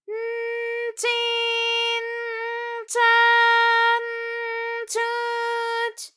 ALYS-DB-001-JPN - First Japanese UTAU vocal library of ALYS.
ch_n_chi_n_cha_n_chu_ch.wav